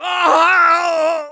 Voice file from Team Fortress 2 German version.
Soldier_painsevere01_de.wav